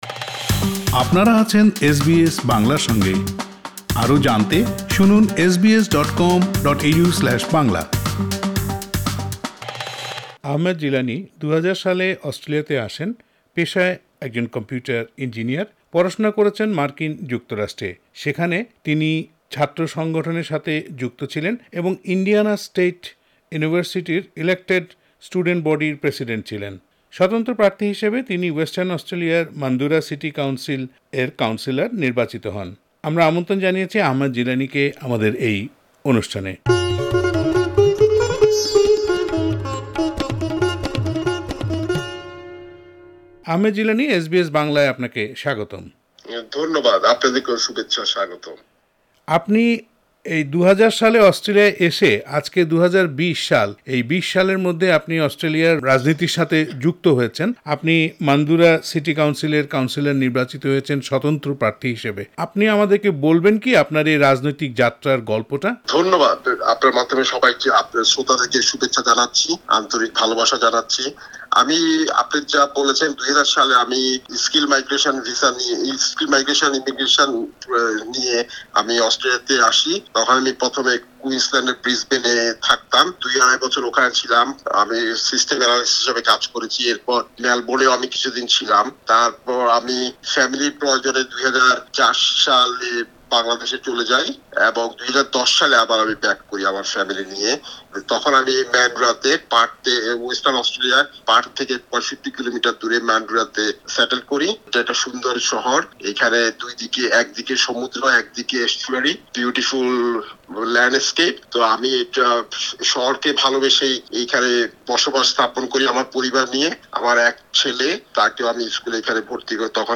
স্বতন্ত্র প্রার্থী হিসেবে ওয়েস্টার্ন অস্ট্রেলিয়ার মানডুরা সিটি কাউন্সিলে কাউন্সিলর নির্বাচিত হয়েছেন বাংলাদেশী আহমেদ জিলানি। এসবিএস বাংলার সঙ্গে কথা বলেছেন তিনি।
কাউন্সিলর আহমেদ জিলানির পুরো সাক্ষাৎকারটি বাংলায় শুনতে উপরের অডিও প্লেয়ারটিতে ক্লিক করুন।